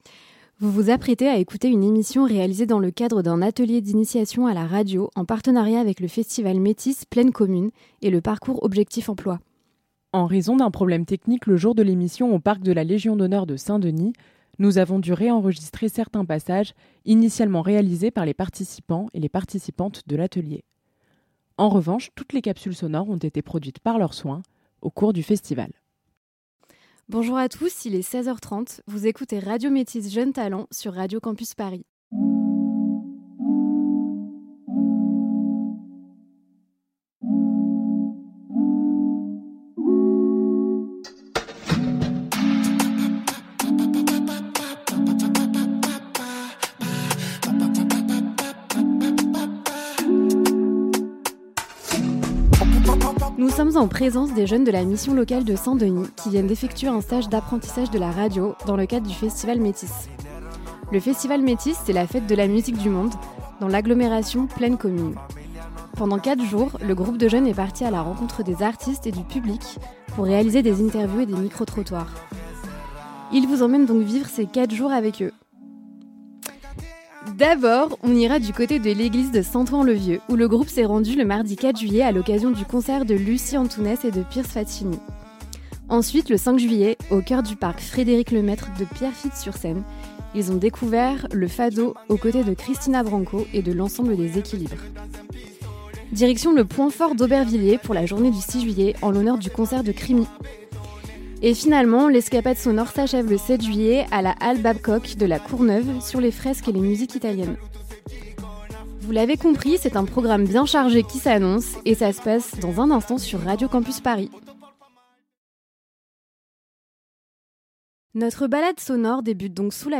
Pendant 4 jours, les jeunes de la mission locale de Saint-Denis sont partis à la rencontre des artistes et du public pour réaliser des interviews et des micro-trottoirs.